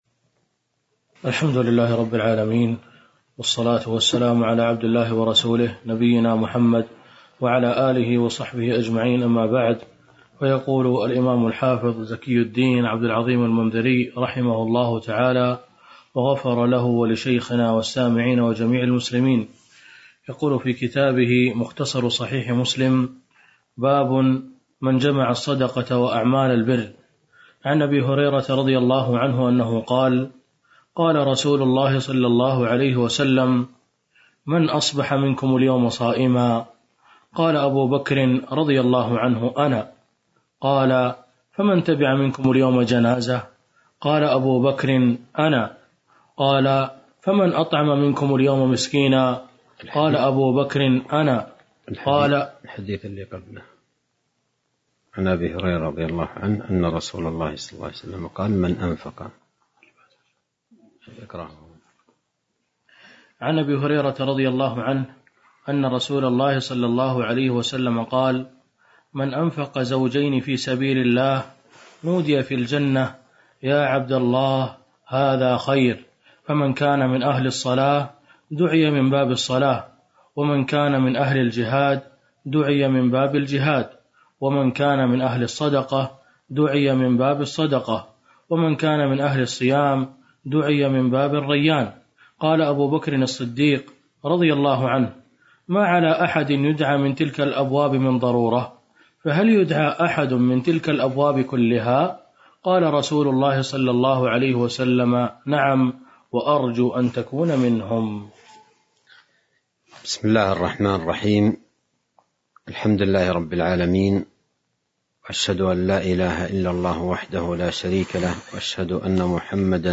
تاريخ النشر ٢١ رجب ١٤٤٢ هـ المكان: المسجد النبوي الشيخ